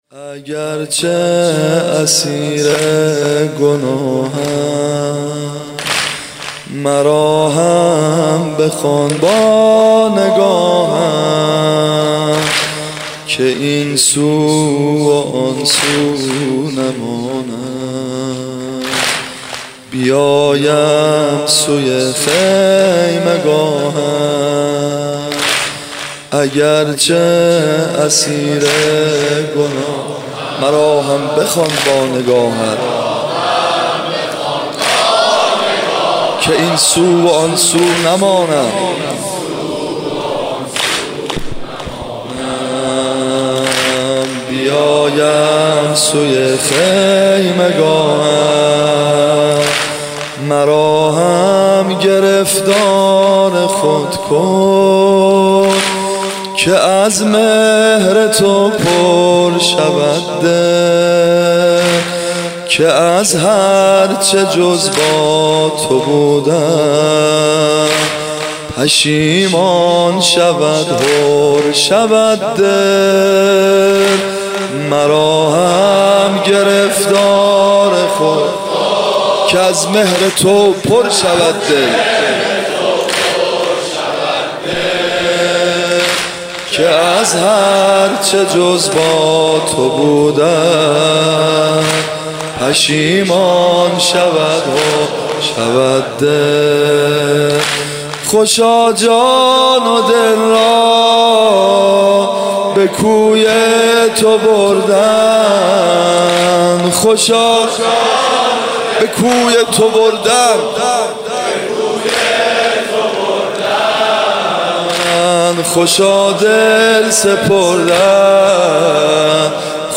صوت مراسم شب چهارم محرم 1438هیئت ابن الرضا(ع) ذیلاً می‌آید: